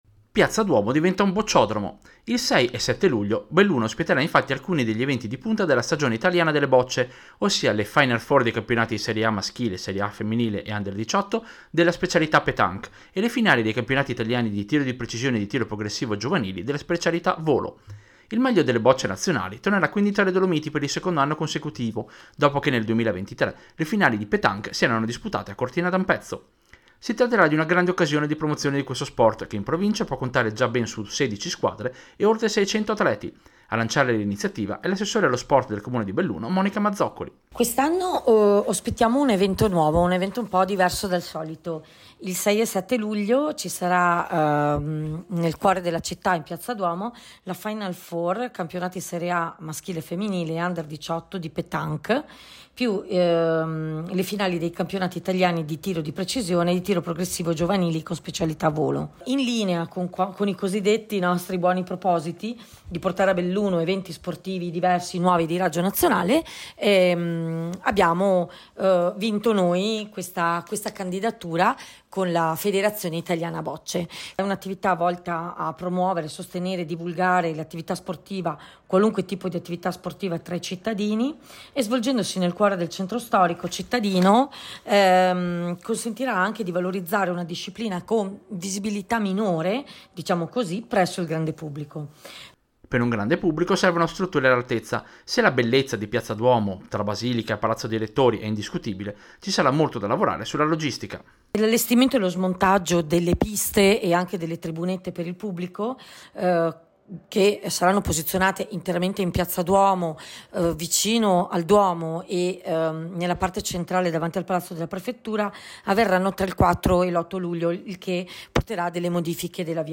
Servizio-Finali-Bocce-Piazza-Duomo.mp3